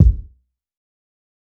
TC Kick 21.wav